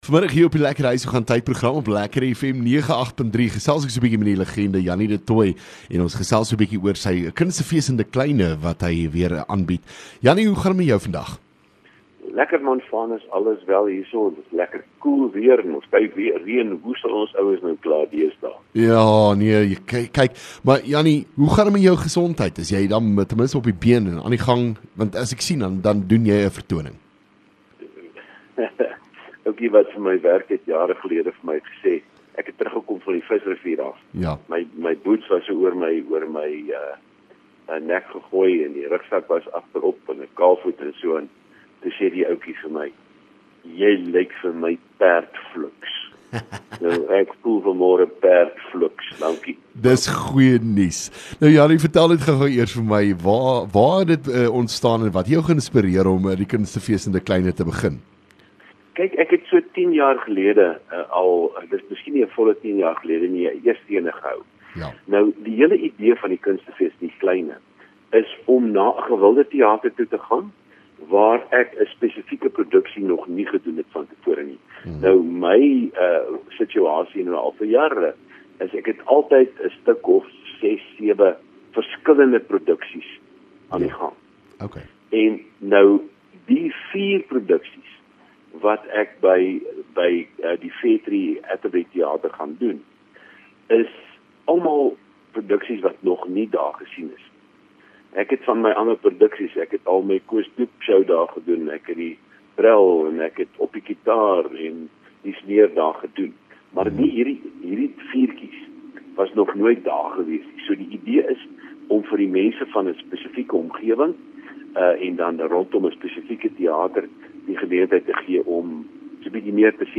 LEKKER FM | Onderhoude